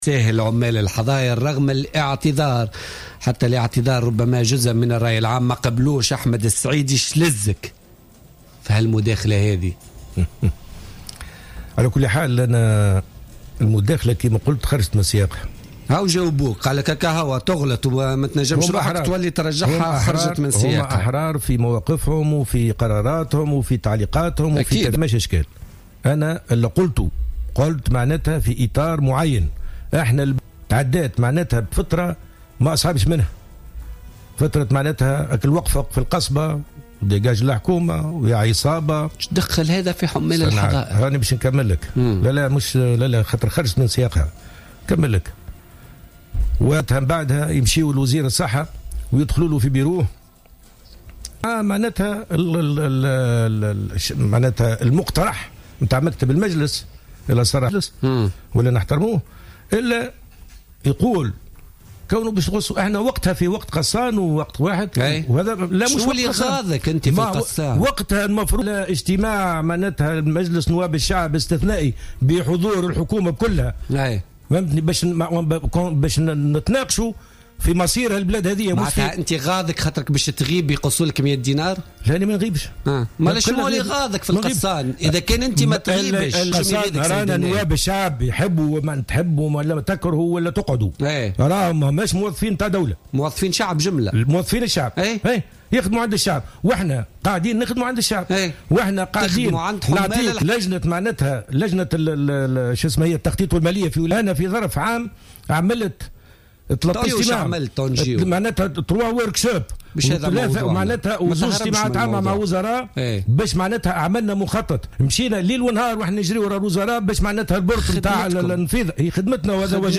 أكد النائب عن حزب المبادرة أحمد السعيدي ضيف بوليتيكا اليوم الخميس 3 مارس 2016 أن احتج على قرار الإقتطاع من منحة النواب بسبب الغيابات لأن الغيابات موجودة في أغلب برلمانات العالم وليست في تونس فقط.